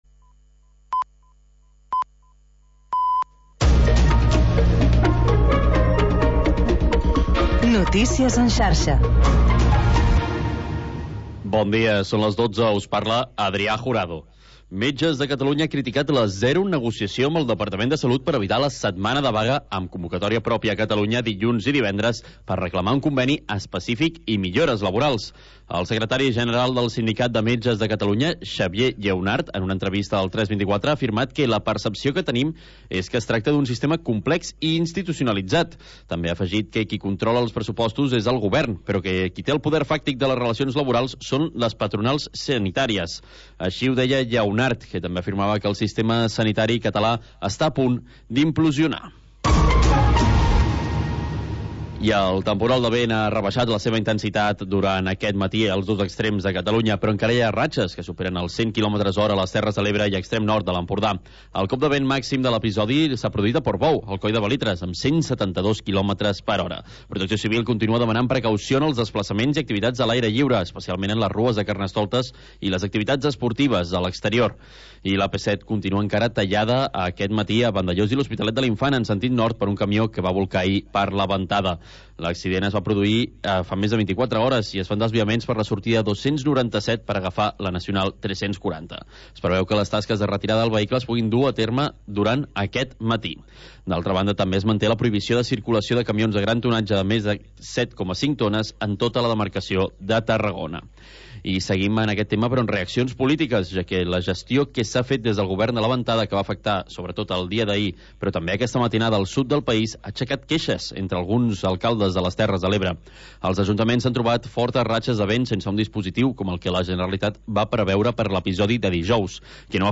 Música nostàlgica dels anys 50, 60 i 70